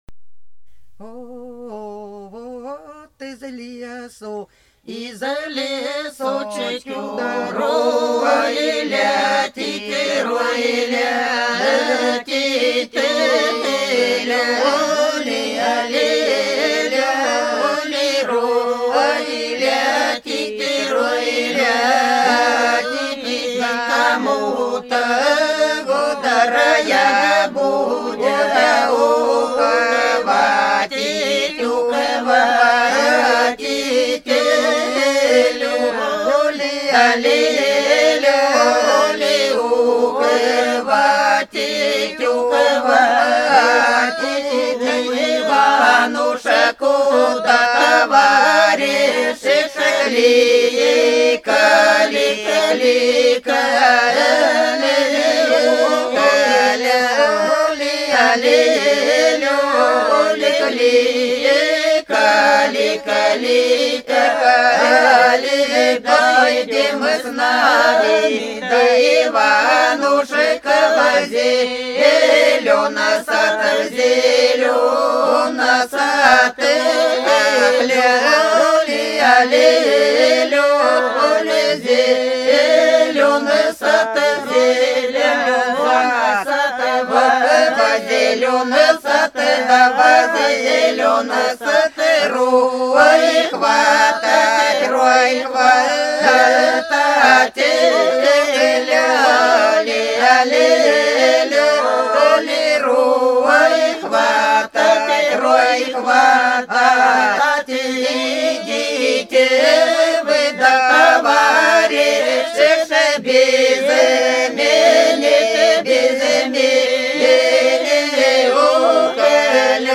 Хороша наша деревня Вот из лесу, из лесу рой летит - свадебная (с. Подсереднее)